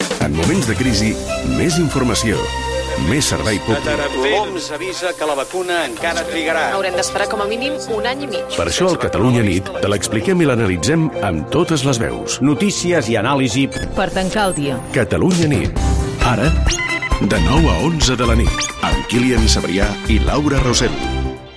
Promoció del programa durant el confinament degut a la pandèmia de la Covid-19